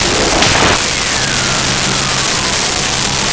Radiosound
radiosound.wav